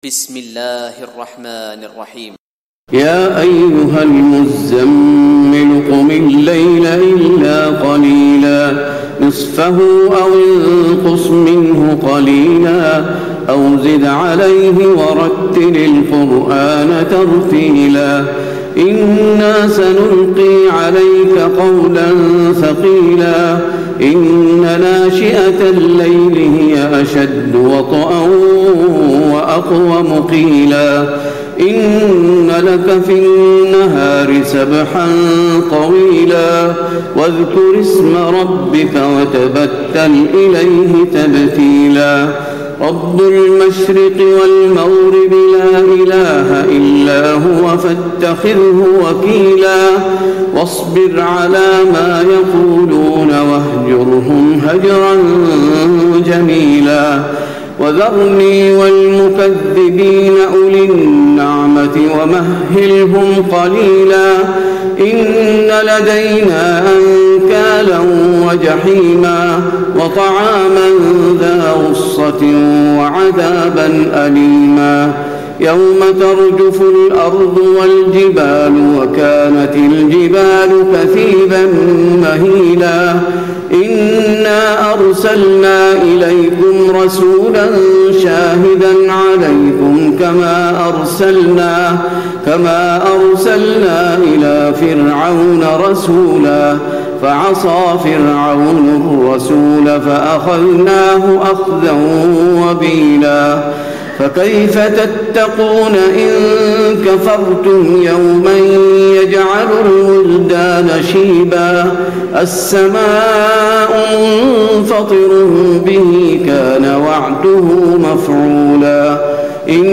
تراويح ليلة 28 رمضان 1436هـ من سورة المزمل الى الإنسان Taraweeh 28 st night Ramadan 1436H from Surah Al-Muzzammil to Al-Insaan > تراويح الحرم النبوي عام 1436 🕌 > التراويح - تلاوات الحرمين